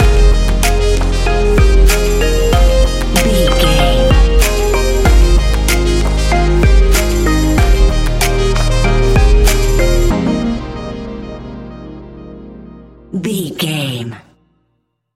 Ionian/Major
D♯
house
electro dance
synths